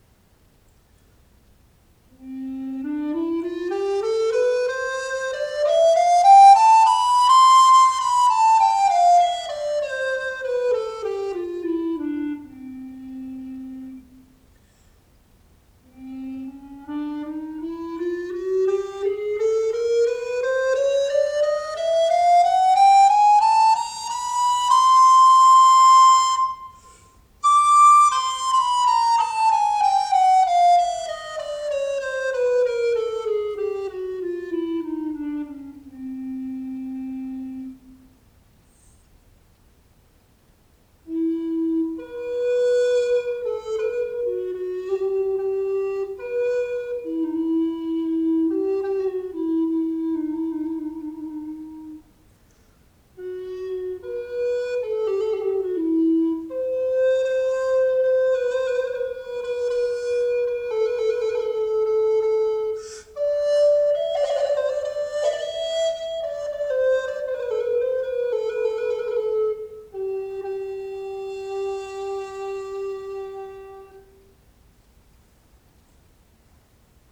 【材質】オリーブ
オリーヴ材は軽いのですが、メープルやペアウッドのような軽い材とは違って音色にはしっかりと輪郭があり、加えて非常に明るい音色がします。ローズやエボニーのようにソロっぽすぎることもなく、ソロでもアンサンブルでも使えます。
今回の選定品はとにかく吹きやすい！高音域まで簡単に出せて、低音も安定しています。